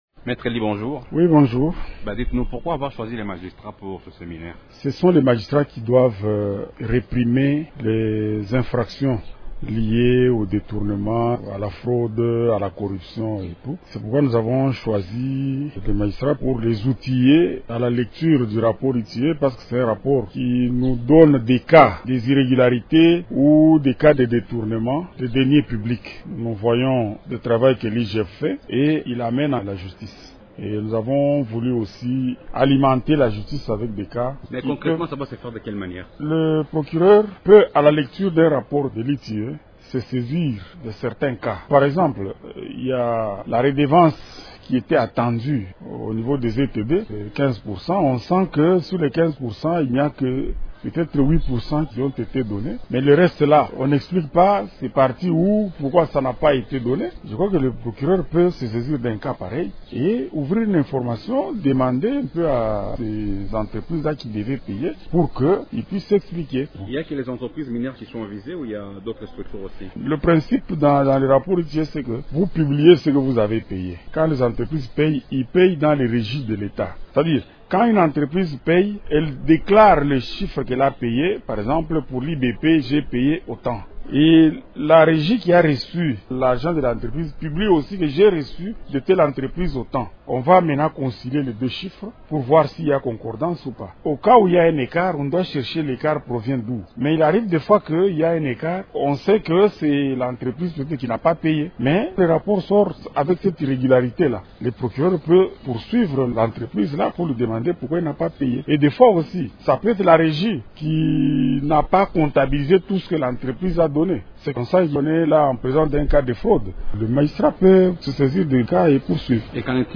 s'entretient avec